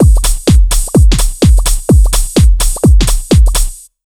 127BEAT6 1-L.wav